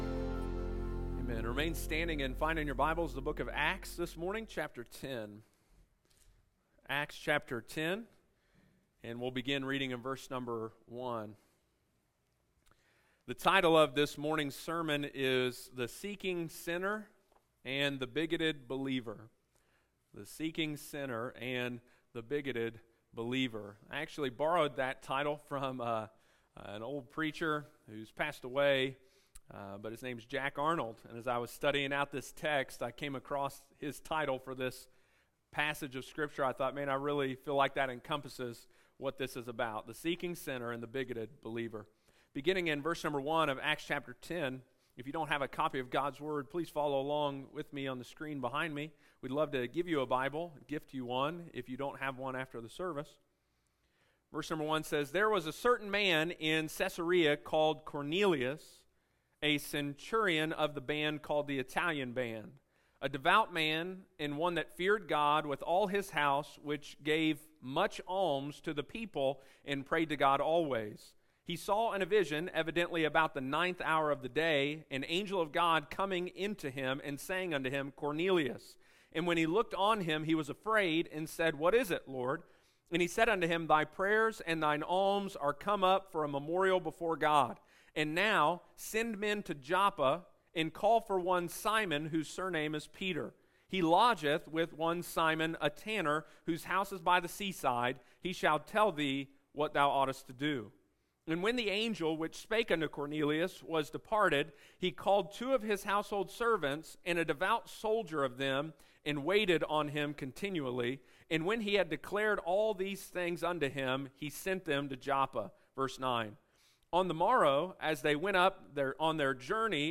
Peter had witnessed the Spirit of God come to the Jewish people and the Samaritans and now in Acts chapter 10 he would witness the gospel spread for the first time to the gentiles. Sunday morning, June 5, 2022.